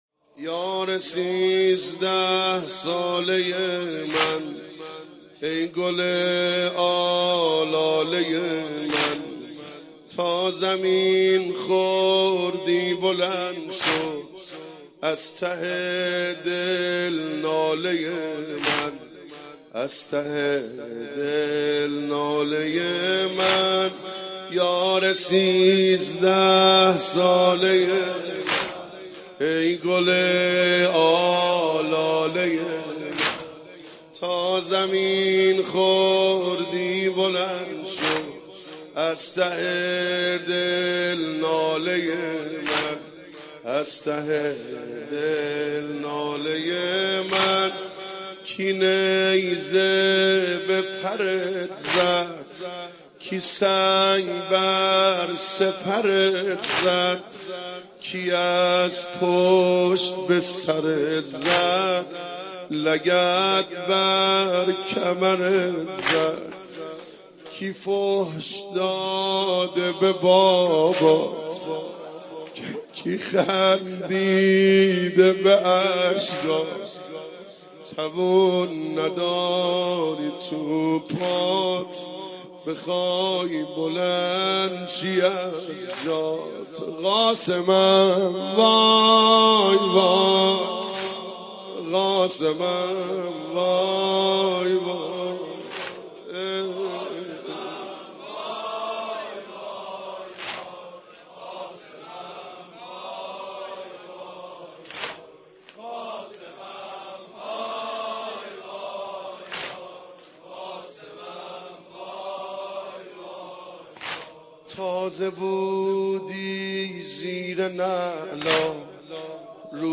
مداحی اربعین